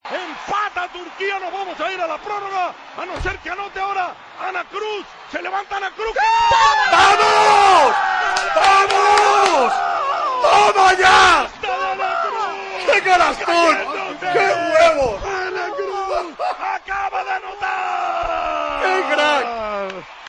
Así narramos la canasta de Anna Cruz que nos metió en semifinales